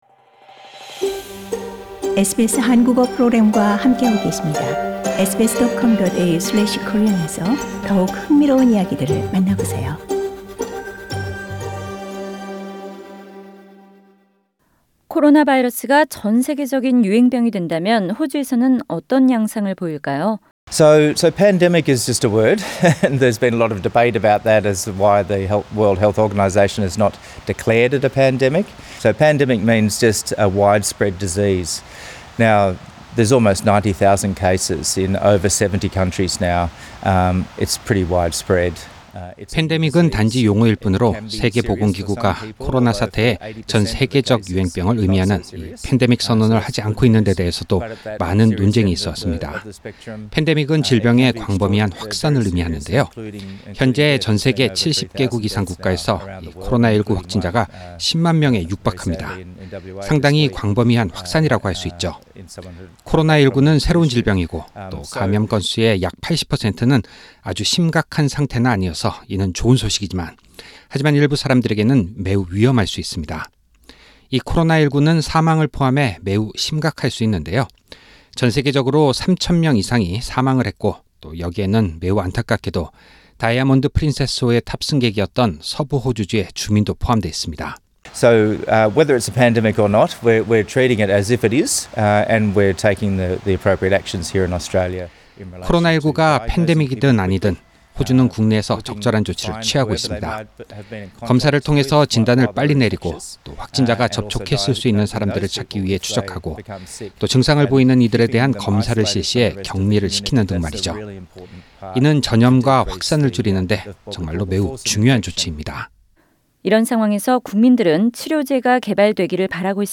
[폴 켈리 연방차석의료관 폴 켈리 의대 교수와의 전체 인터뷰 내용은 상단의 팟 캐스트를 통해 들으실 수 있습니다] Share